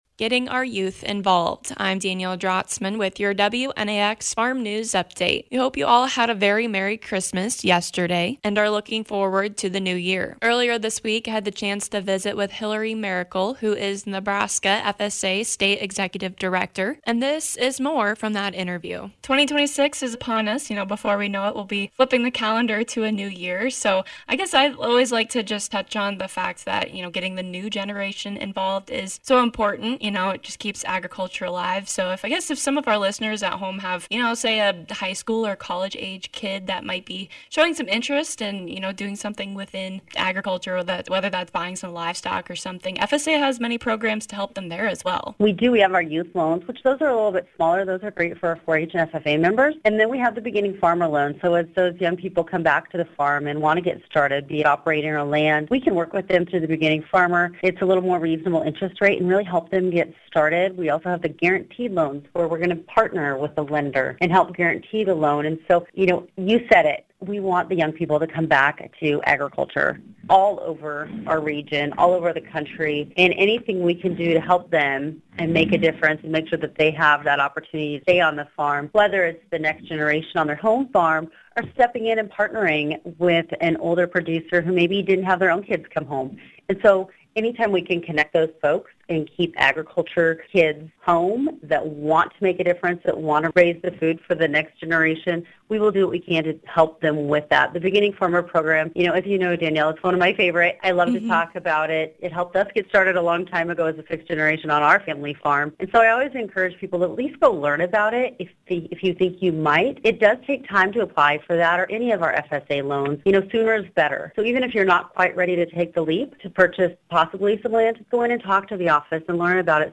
Getting youth involved in agriculture with the help of the FSA. Hear from Hilary Maricle NE FSA State Executive Director.